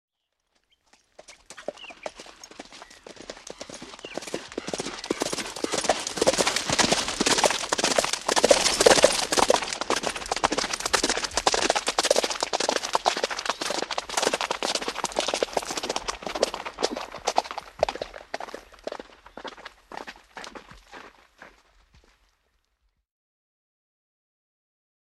دانلود صدای اسب 38 از ساعد نیوز با لینک مستقیم و کیفیت بالا
جلوه های صوتی